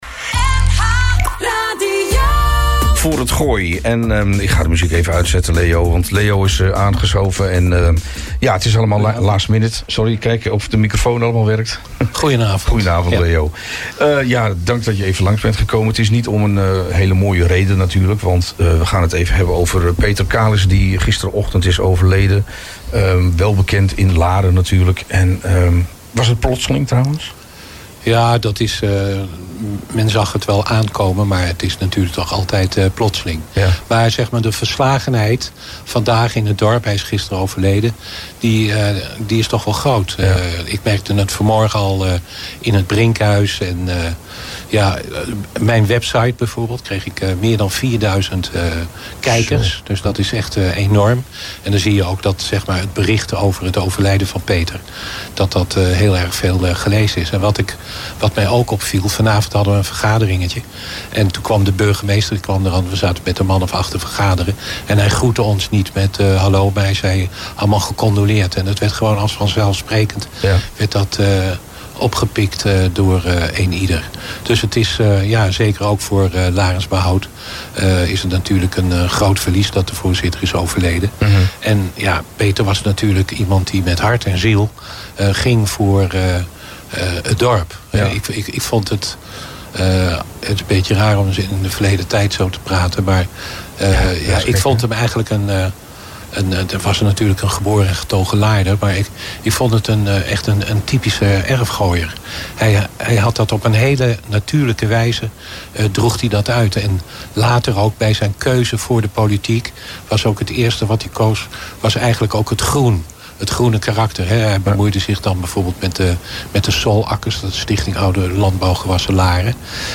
All Inclusive - Gesprek